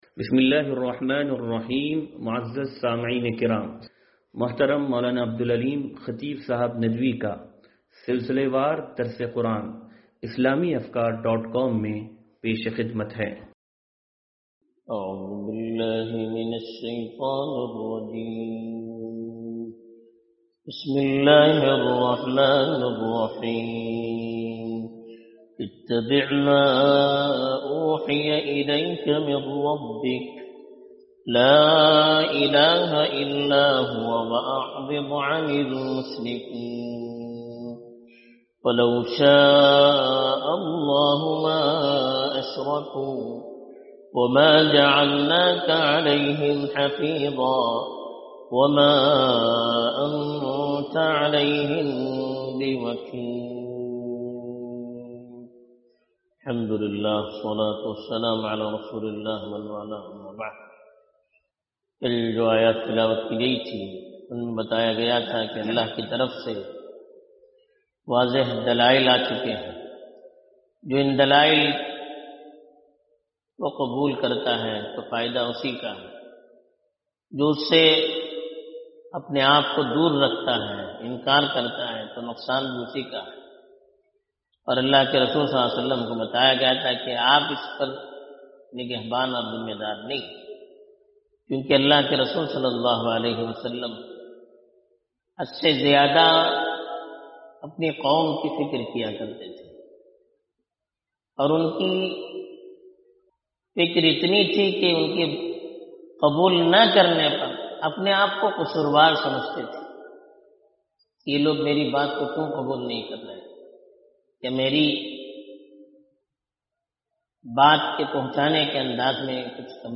درس قرآن نمبر 0556